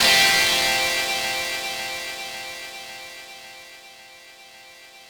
ChordFadd9.wav